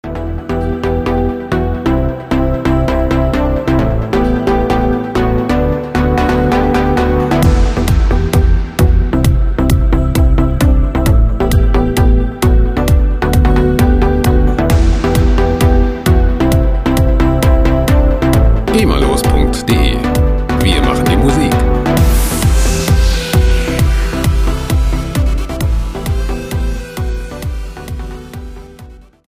• Progressive EDM
gema-freier Progressive Trance Loop